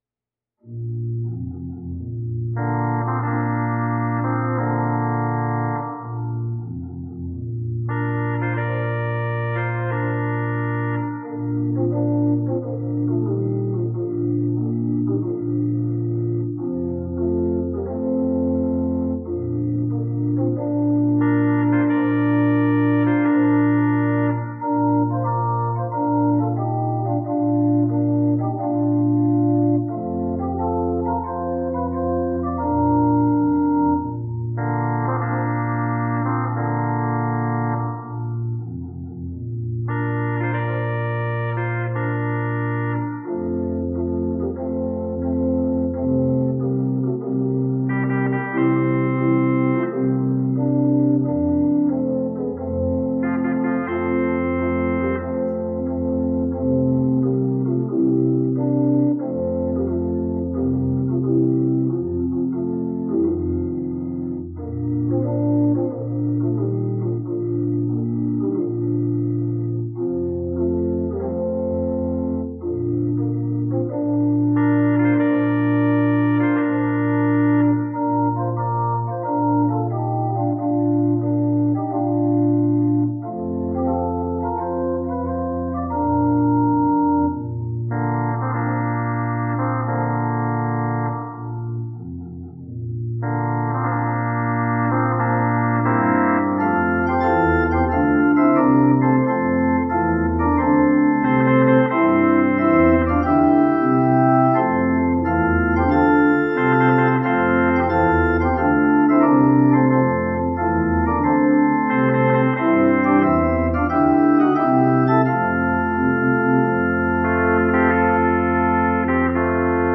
We Are All Enlisted (Montclair) – The Organ Is Praise
This is a setting of a hymn tune that is well-loved in my faith.
You may notice that this setting isn’t terribly loud or boisterous, except in the center section.